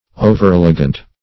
Overelegant \O"ver*el"e*gant\